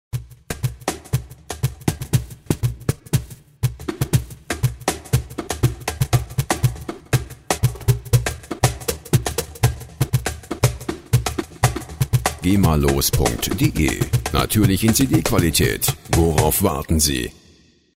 Tempo: 120 bpm